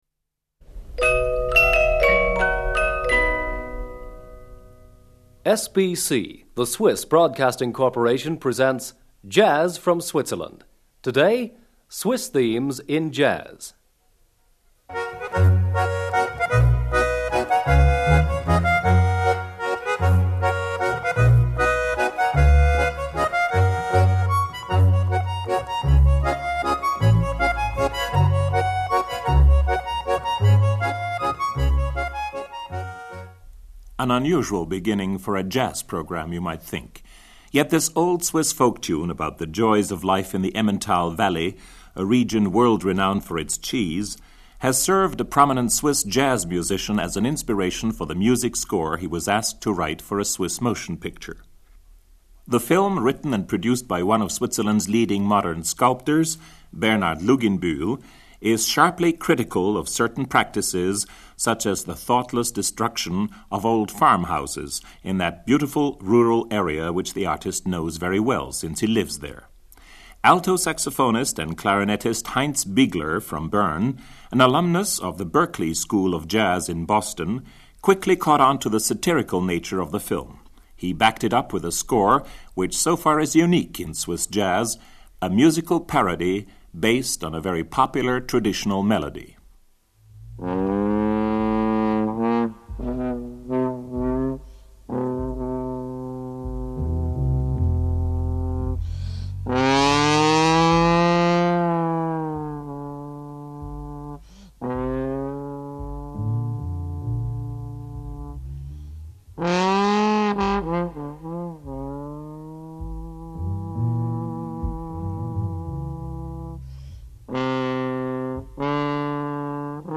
trumpet